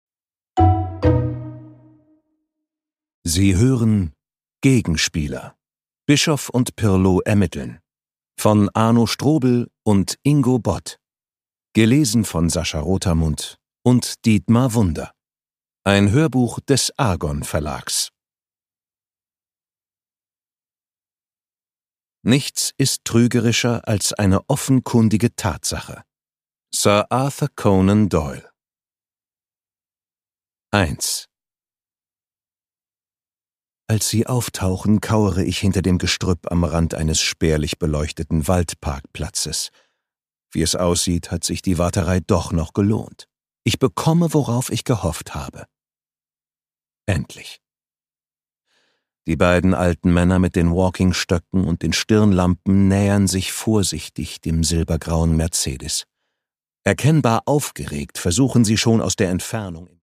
Produkttyp: Hörbuch-Download
Gelesen von: Sascha Rotermund, Dietmar Wunder